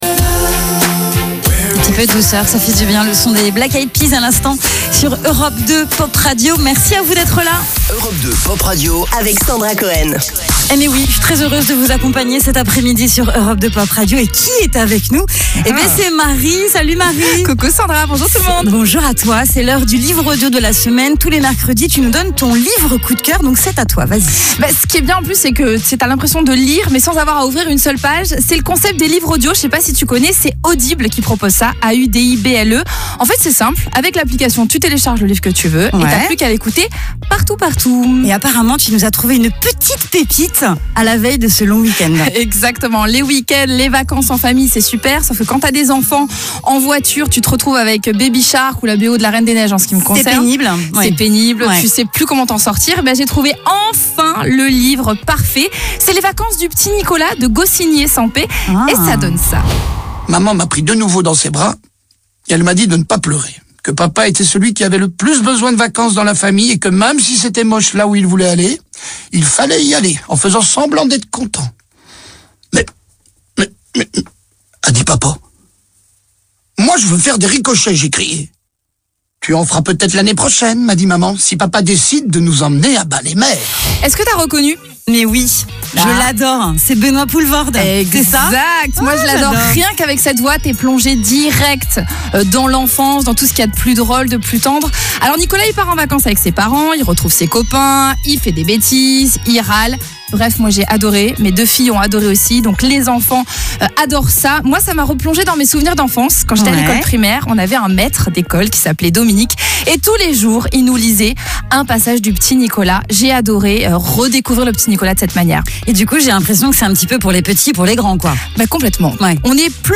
Chaque semaine, une chronique « Le livre audio de la semaine » est intégrée en direct dans les matinales et créneaux clés d’Europe 1, RFM et Europe 2. Animées par les voix emblématiques des stations, ces prises de parole courtes (30″) mêlent extrait sonore du livre et pitch de recommandation.